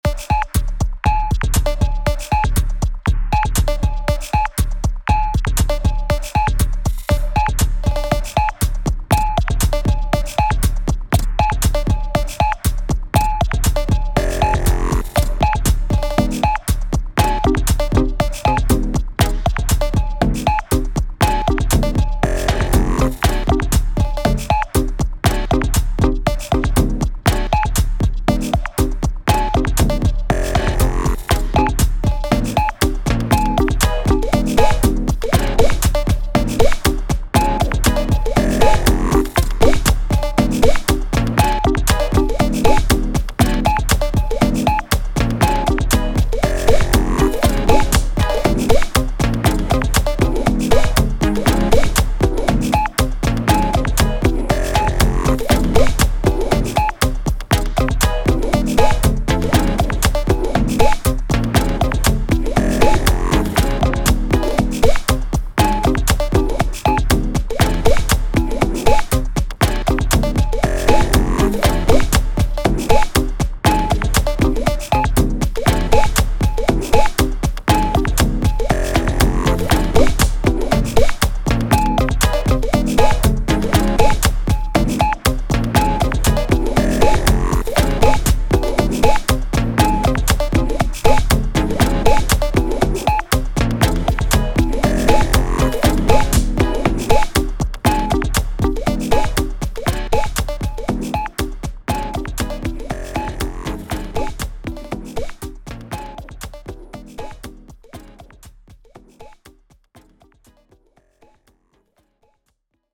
タグ: Beat チュートリアル/解説 パズル/謎解き 不思議/ミステリアス 電子音楽 音少なめ/シンプル